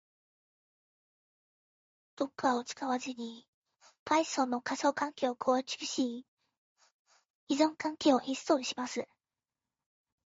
まずは、男性の声を女性の声に変換してみます。
まずは女声を選択します。
はい、女性の声に変換されていますね！